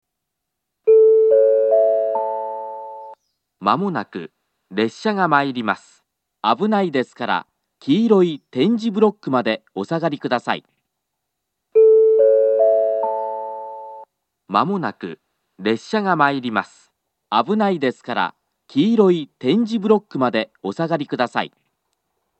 接近放送は何故か上下とも１番線のスピーカーから流れます。
１番線接近放送